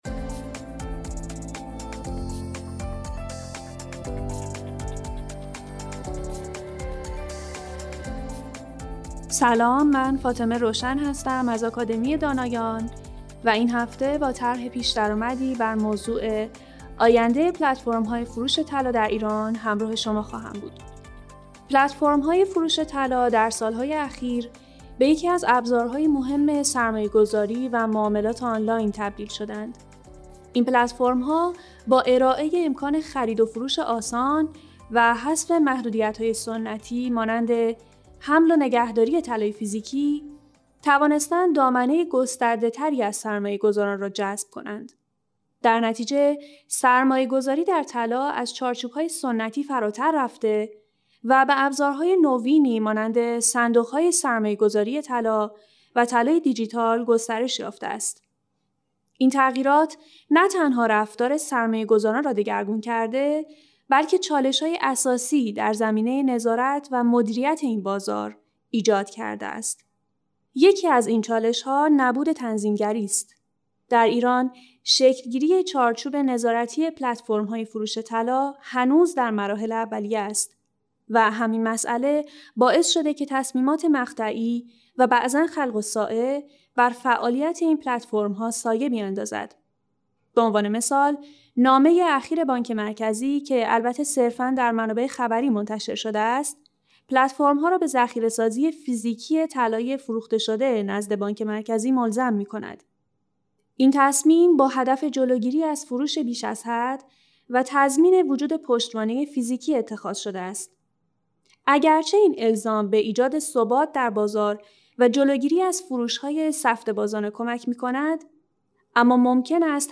فایل صوتی مناظره